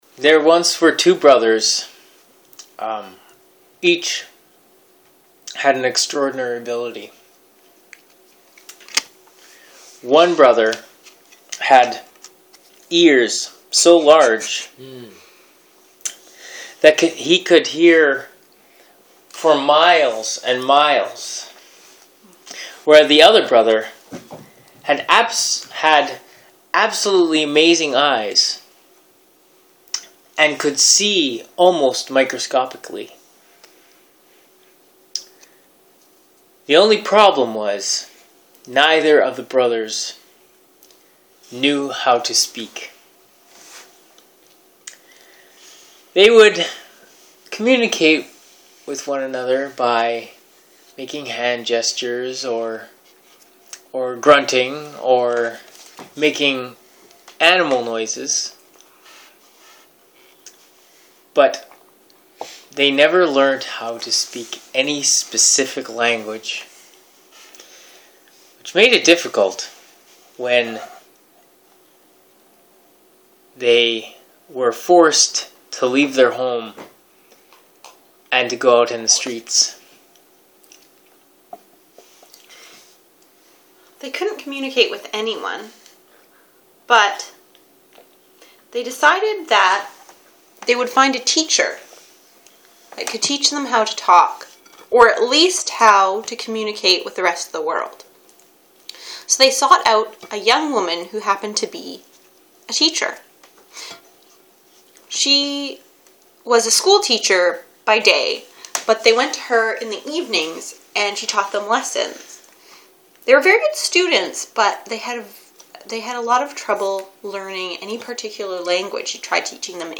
And the sugestion is....Brothers To hear the Rascals tell the story...CLICK HERE A change of paragraph is a change of Rascal telling the story.